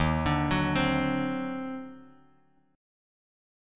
Listen to D#mM13 strummed